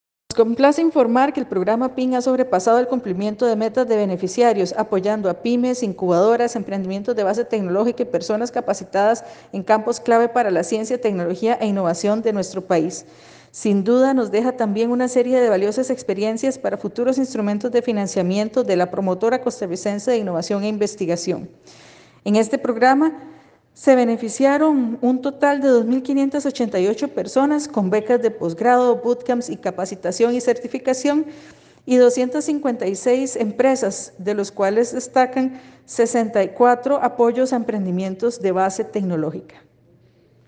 Declaraciones de la ministra Paola Vega Castillo sobre cierre de implementación del Programa de Innovación y Capital Humano para la Competitividad (PINN)